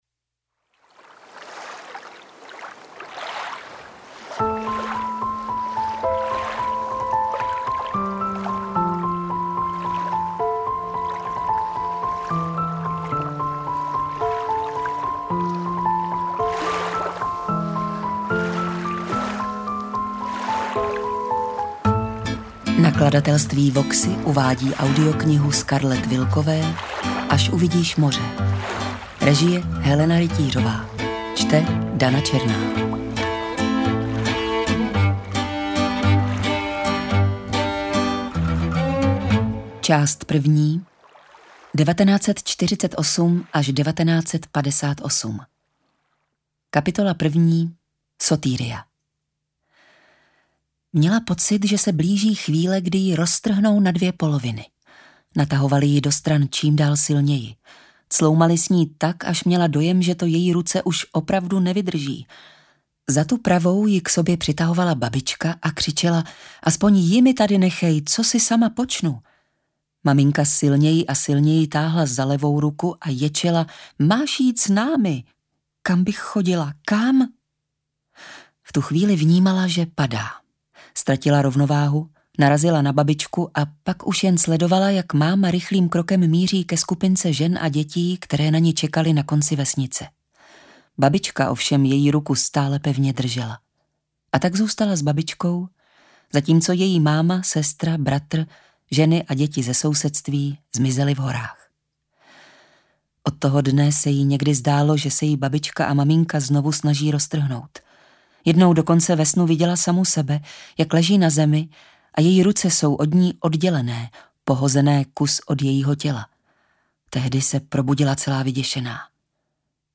Interpret:  Dana Černá
AudioKniha ke stažení, 61 x mp3, délka 13 hod. 24 min., velikost 745,8 MB, česky